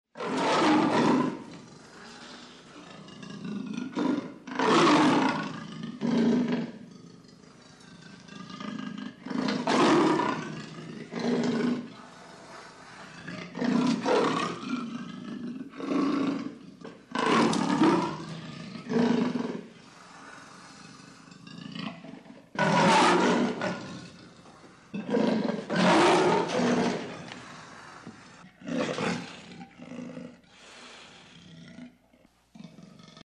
zvuki-leoparda_002
zvuki-leoparda_002.mp3